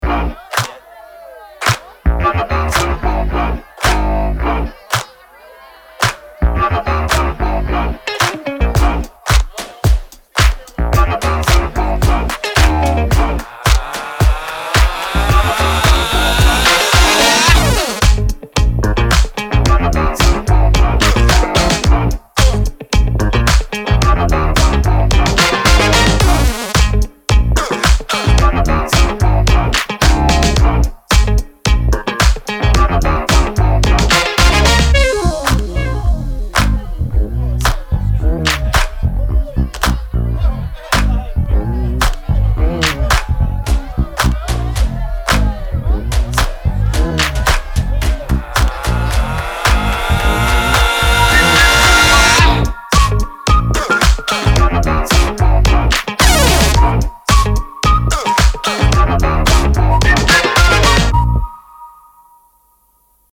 Saturation, warmth, and mechanical movement for your mixes.
-1dB True Peak
These are real-session settings, not extreme demonstrations.